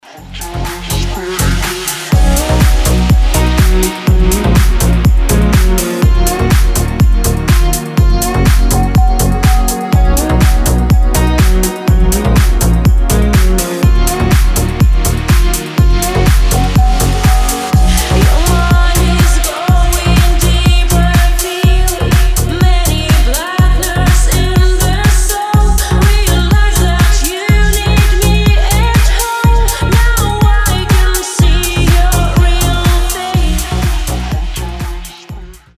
женский вокал
deep house
космические
Классный deep house